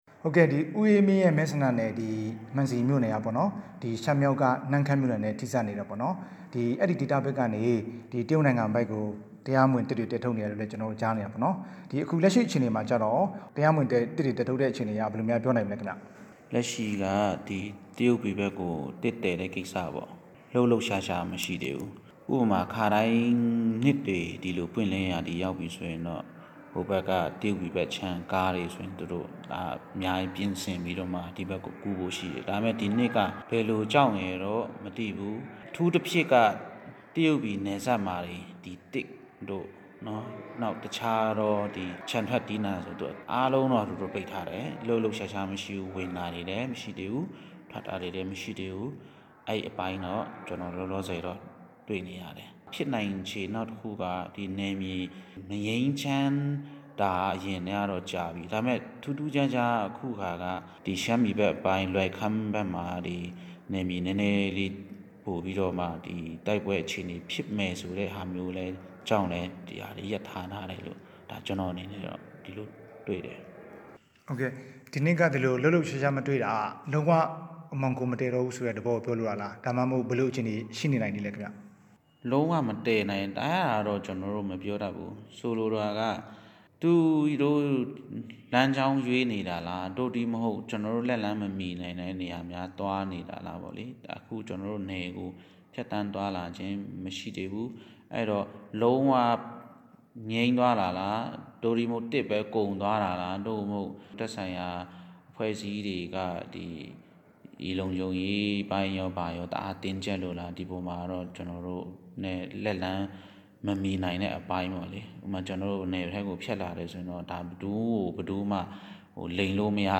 တရုတ်ပြည်ဘက် သစ်မှောင်ခိုသယ်ယူမှုအကြောင်း မေးမြန်းချက်